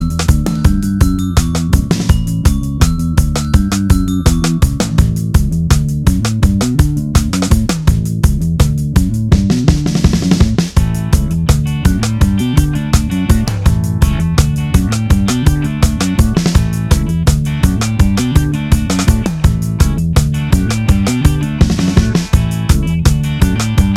Minus Main Guitar Ska 2:44 Buy £1.50